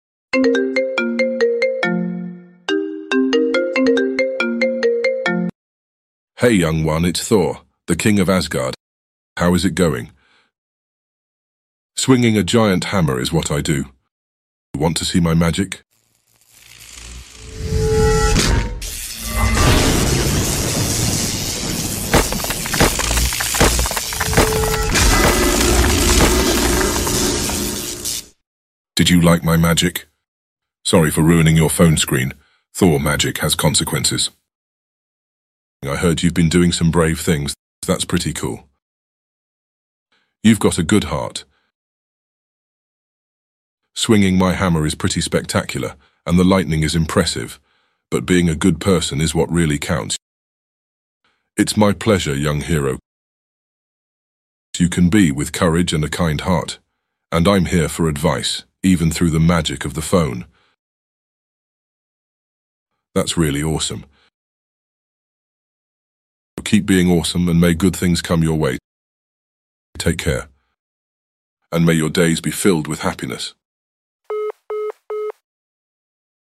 🌩🔥 Get ready for an epic fake call prank filled with thunderous laughs, heroic banter, and maybe even some hammer troubles!
You Just Search Sound Effects And Download. tiktok funny sound hahaha Download Sound Effect Home